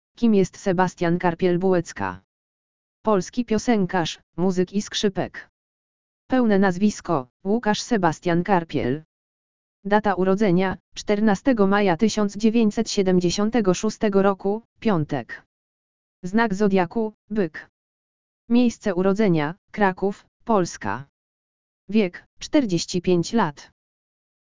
audio_lektor_urodziny_sebastiana_karpielbulecki.mp3